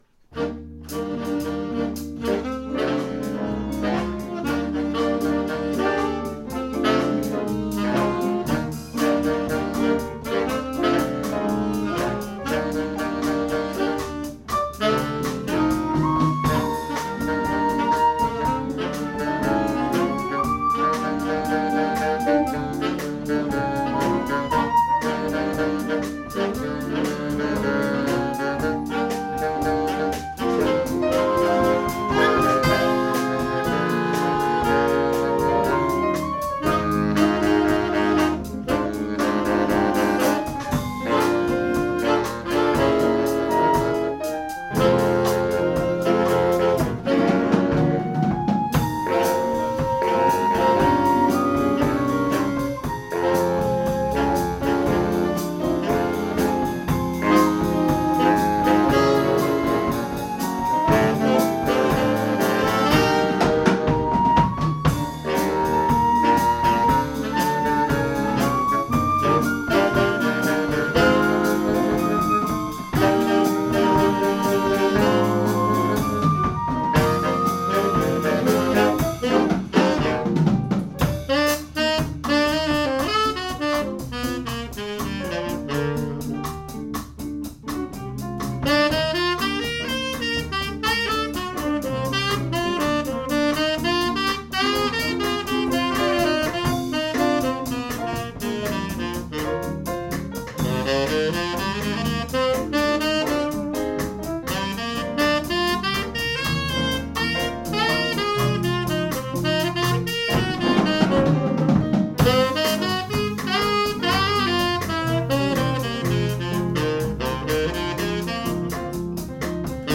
Jazz-nonetten på Lautrupgaard - optagelser 2025
Lyd i mp3-format, ca. 192 kbps, optaget stereo - ikke studieoptagelser!.